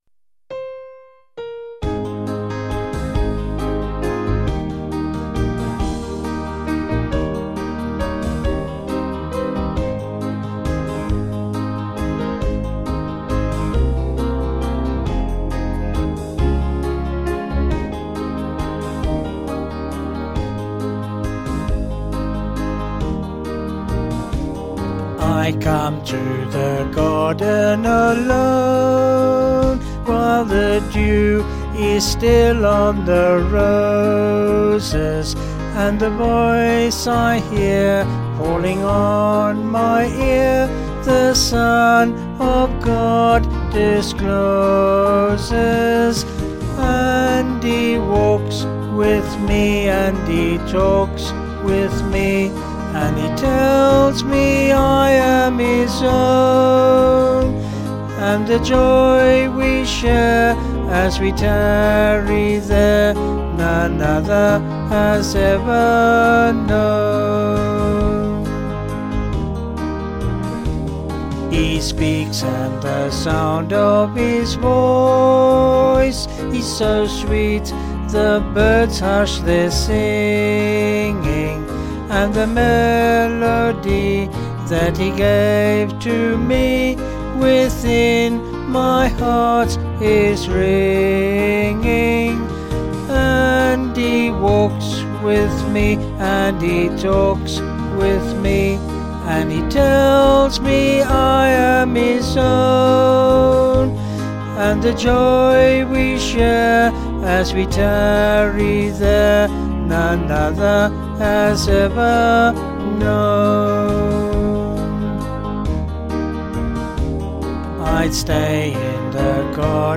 3/Ab
Vocals and Band   264.3kb Sung Lyrics